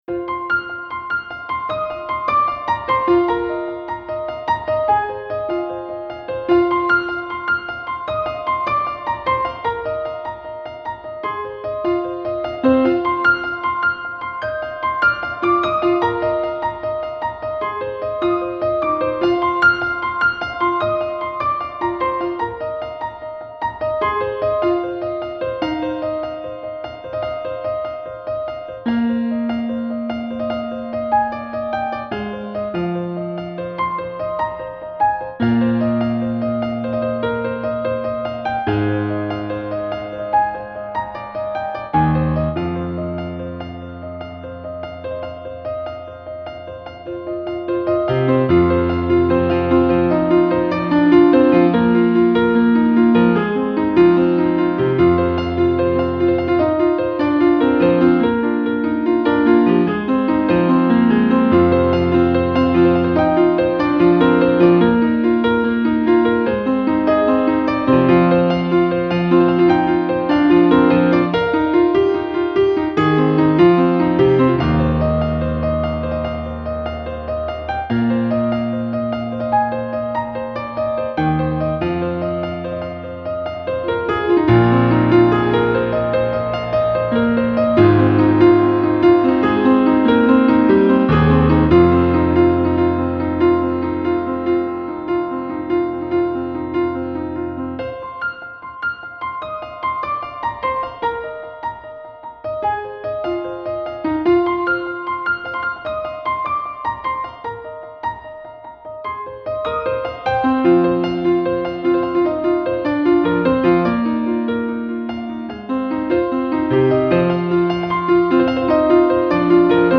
音乐流派；新世纪音乐 (New Age) / 环境音乐 (Ambient Music)
如天降甘露般纯净无暇 看见心灵深处温暖阳光